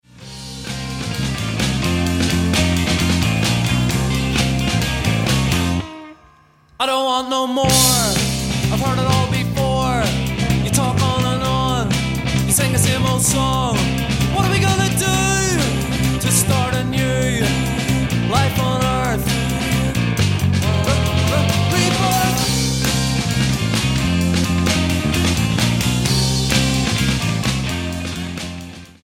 The rock group